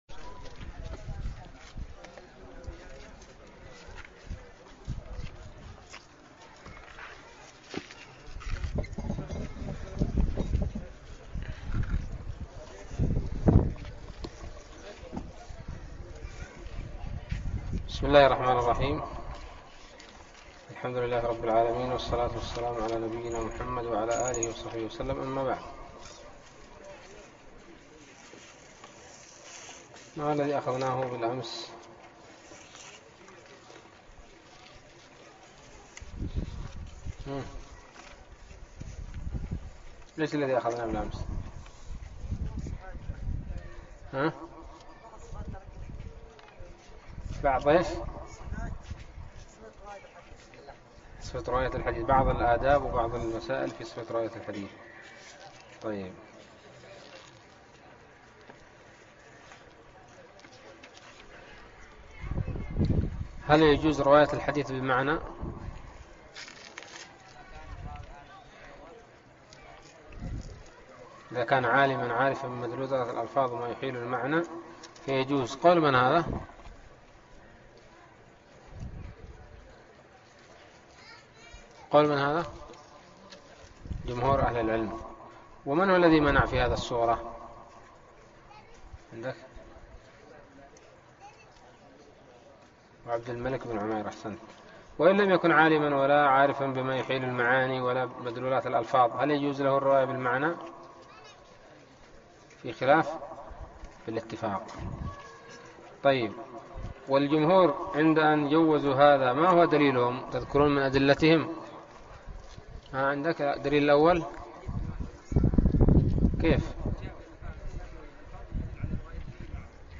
الدرس الرابع والأربعون من الباعث الحثيث